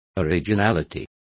Complete with pronunciation of the translation of originality.